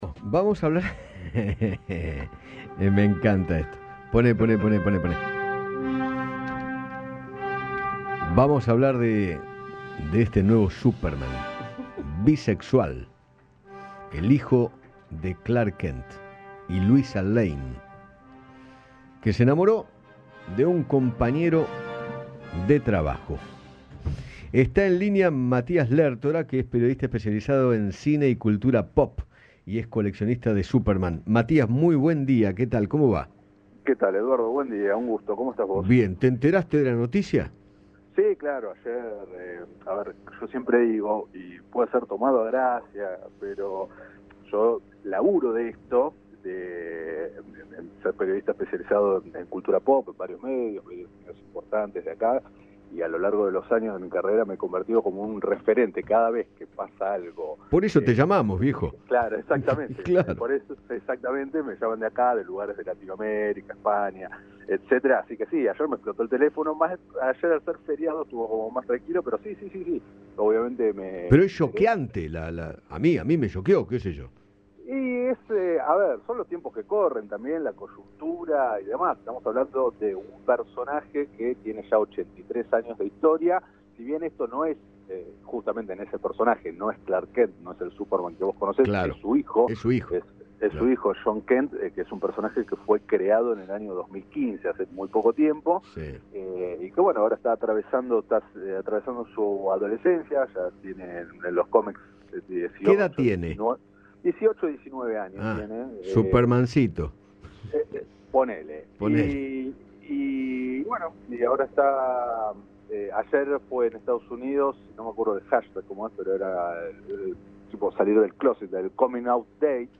El-nuevo-Superman-es-bisexual-Radio-Rivadavia-AM630.mp3